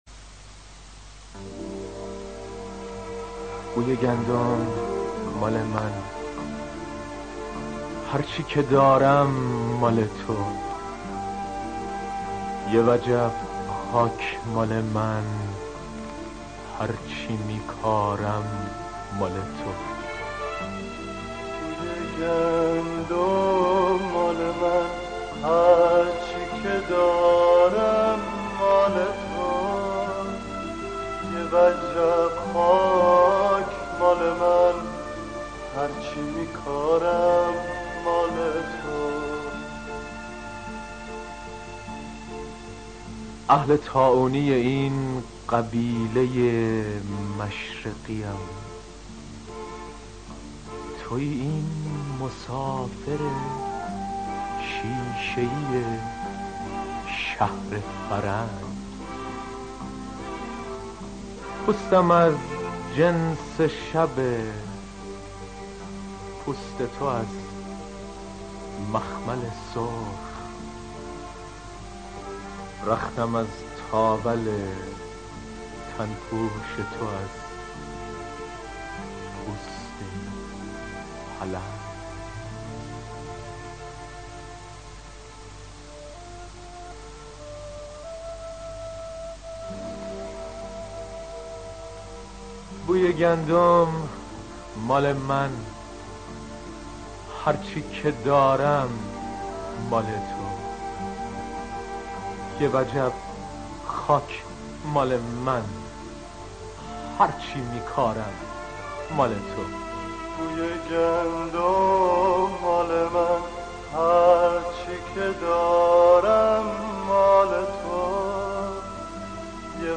دانلود دکلمه بوی گندم با صدای شهیار قنبری
دانلود نسخه صوتی دکلمه دانلود / گوینده: [شهیار قنبری]